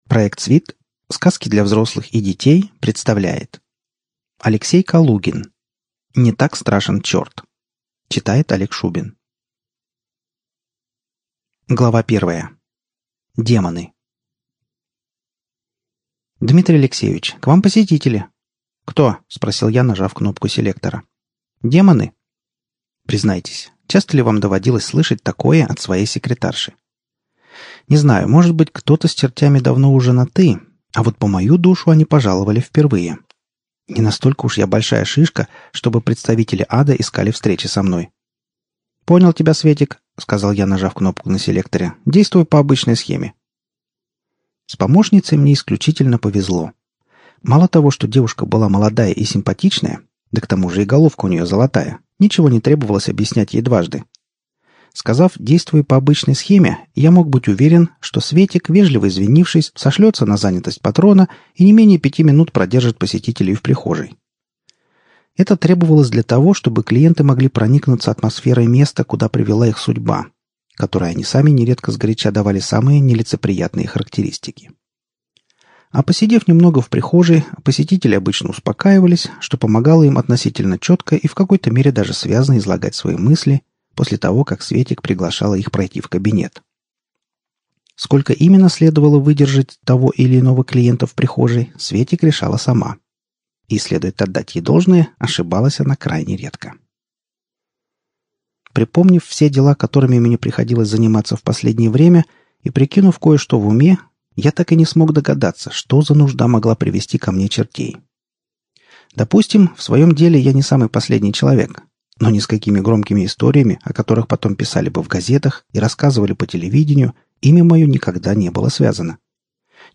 Аудиокнига Не так страшен черт | Библиотека аудиокниг